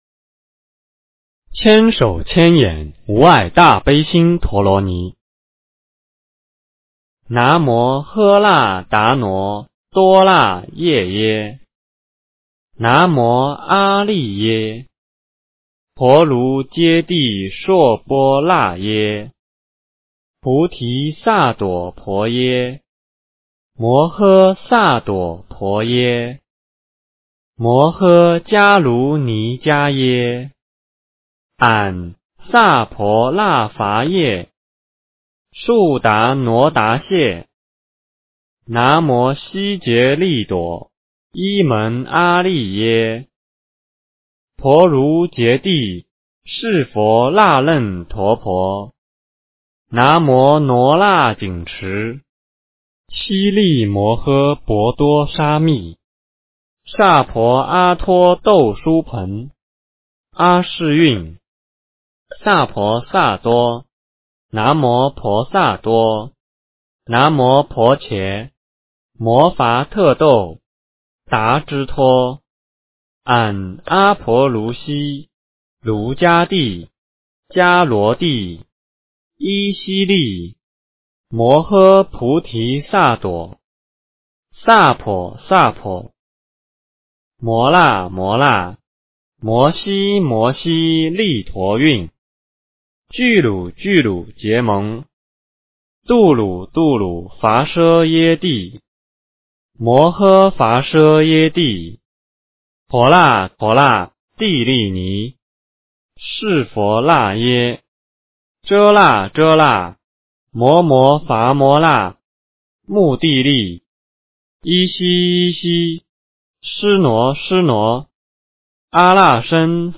千手千眼无碍大悲心陀罗尼-大悲咒 诵经 千手千眼无碍大悲心陀罗尼-大悲咒--推荐 点我： 标签: 佛音 诵经 佛教音乐 返回列表 上一篇： 心经 下一篇： 大悲咒 相关文章 六字真言--Various Artists 六字真言--Various Artists...